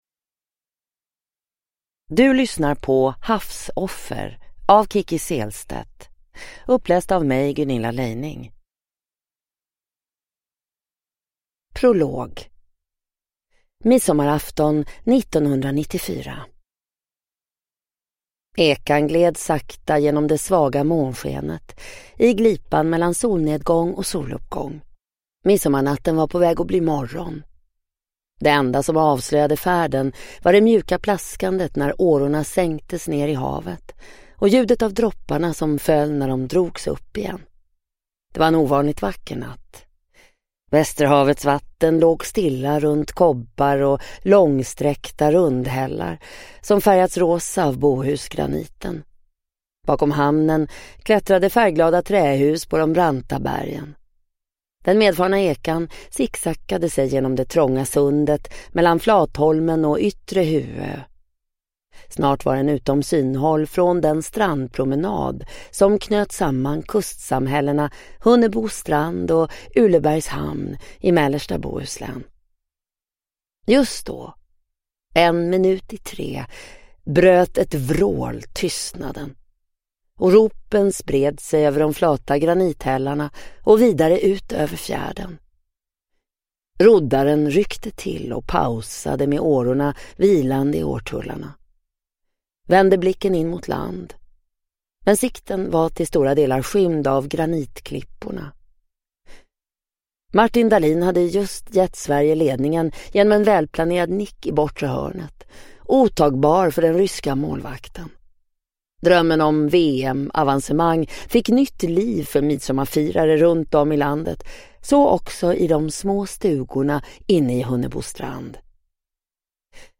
Havsoffer (ljudbok) av Kicki Sehlstedt